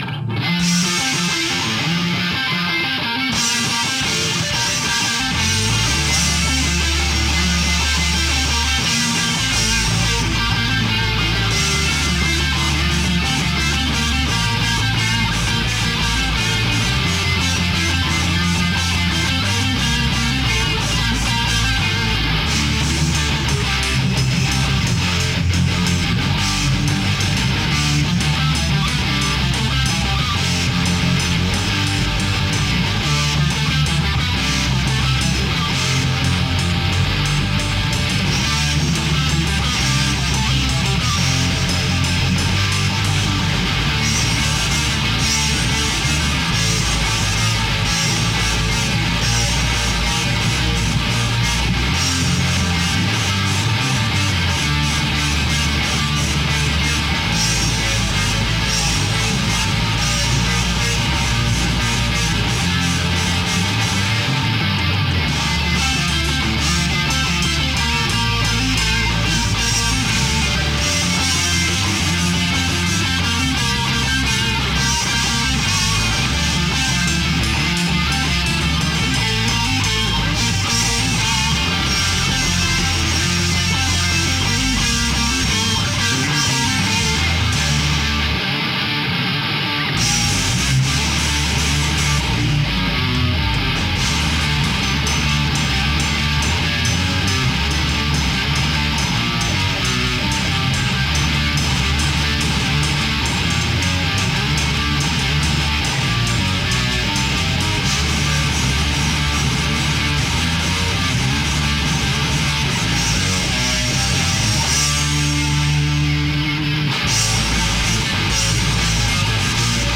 Also while in Bellingham I played in a metal band called CÅVE.
The June 2007 CÅVE sessions produced a couple of decent recordings, included herein.